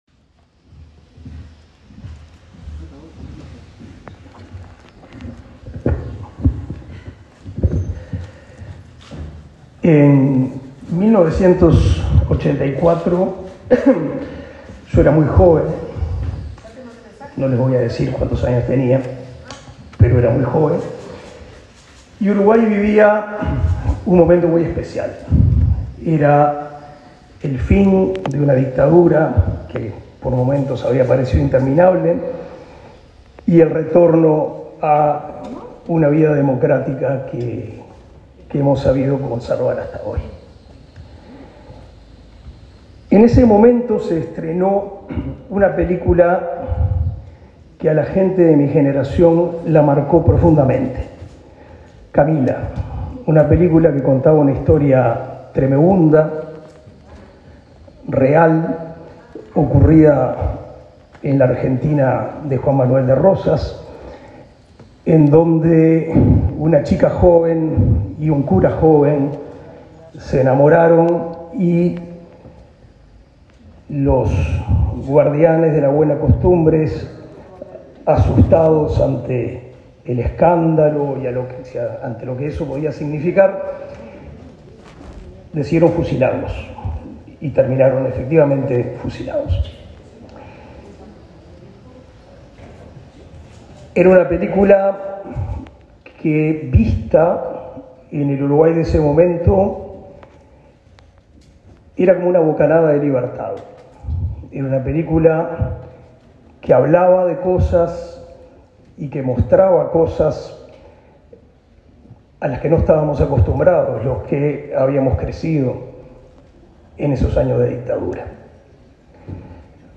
Palabras del titular del MEC, Pablo da Silveira
Palabras del titular del MEC, Pablo da Silveira 23/05/2023 Compartir Facebook X Copiar enlace WhatsApp LinkedIn La Dirección Nacional de Cultura, del Ministerio de Educación y Cultura (MEC), entregó, este 23 de mayo, la Medalla Delmira Agustini al actor español Imanol Arias. En la ceremonia participó el ministro de Educación y Cultura, Pablo da Silveira.